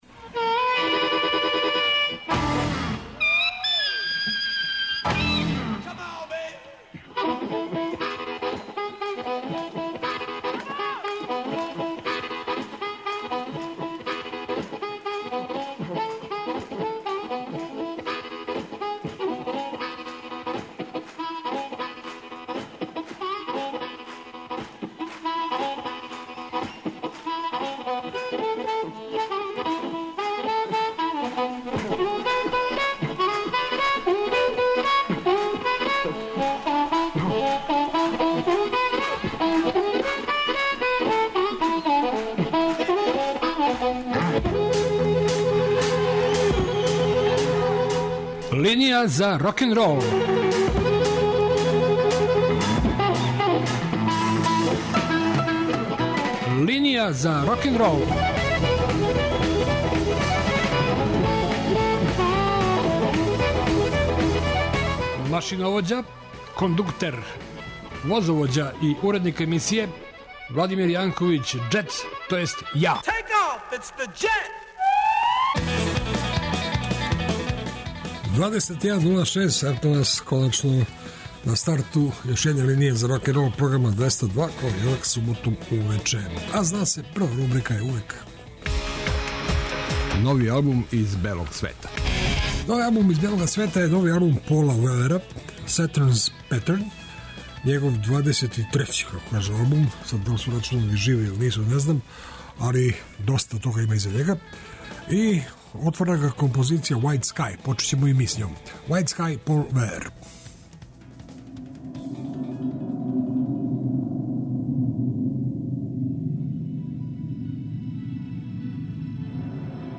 И ове суботе вас очекују стандардне рубрике и много нове музике.